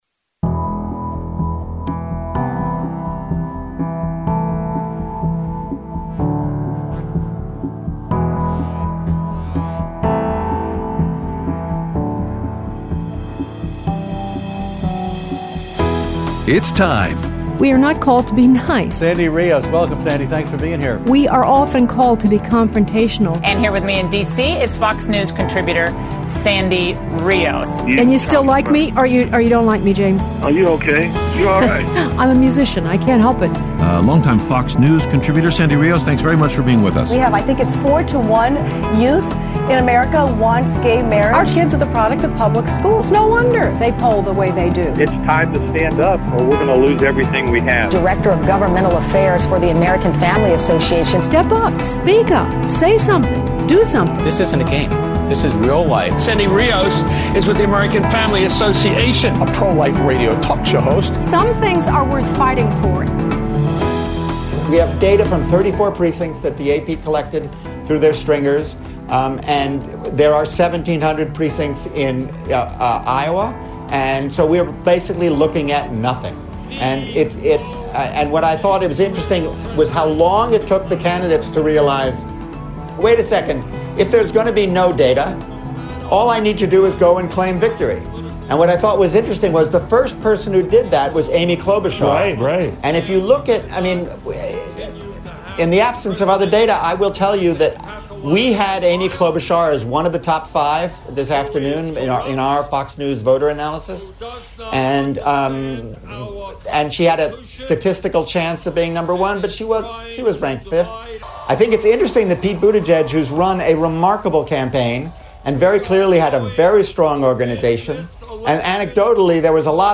Aired Tuesday 2/4/20 on AFR 7:05AM - 8:00AM CST